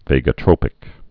(vāgə-trōpĭk, -trŏpĭk)